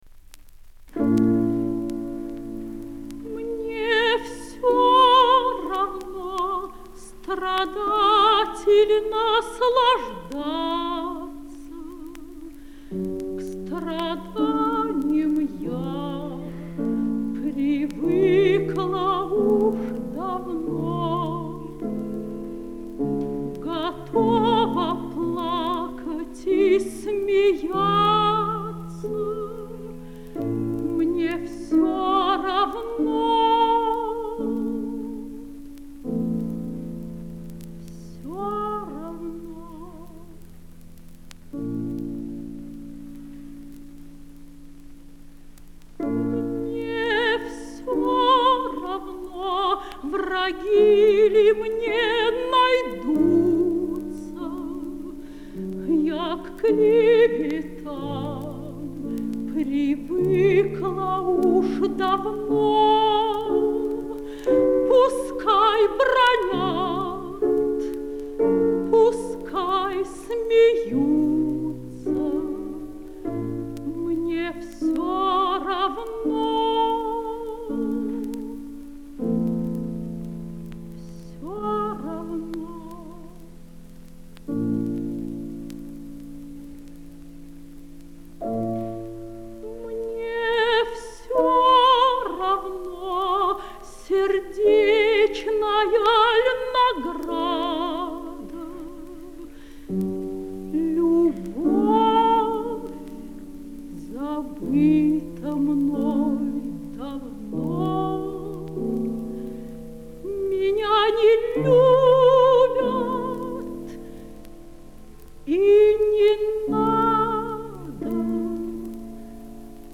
ф-но). Москва, 1953 (live).